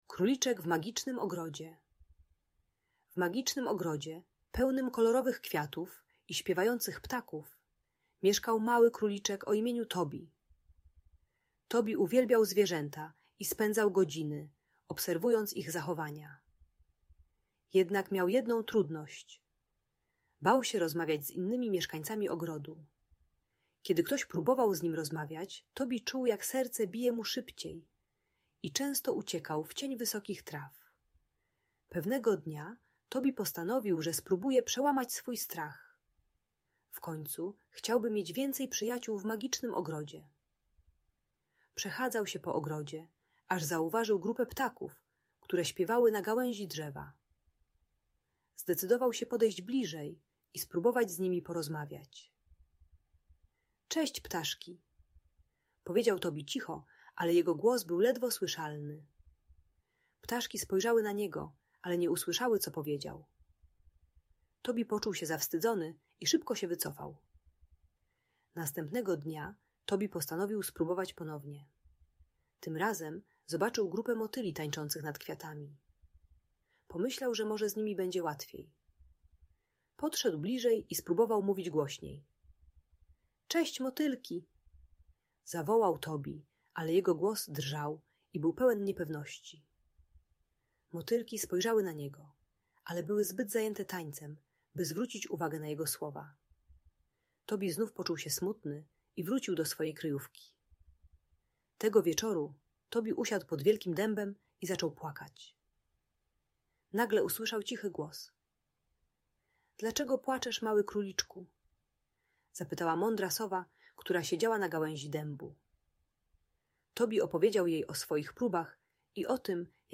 Króliczek Tobi w Magicznym Ogrodzie - Audiobajka